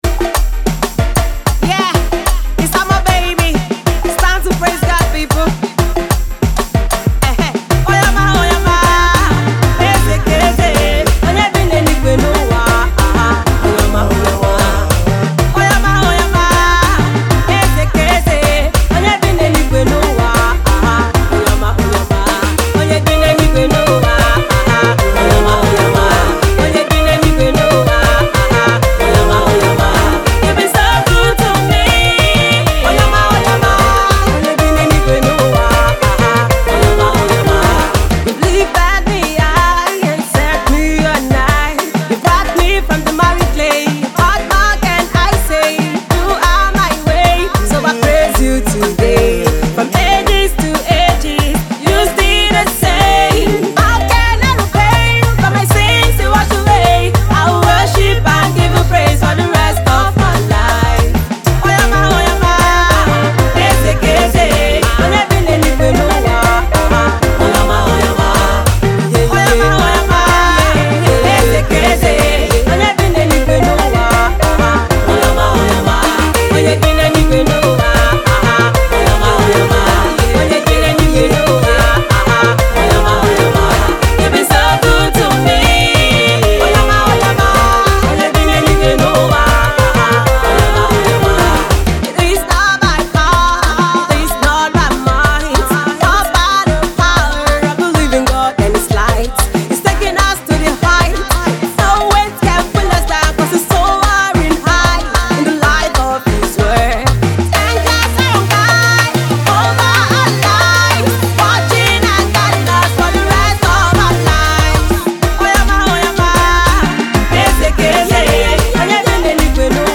praise song
adding his sonorous voice to give the song a splendid feel